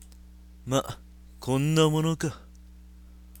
RPG戦闘終了後キャラクター台詞です。
③、④あたりはA、B然して声が違わないような･･･。
しっかし、マイクの集音力高すぎです。
マウスのクリック音まで入ってますよ。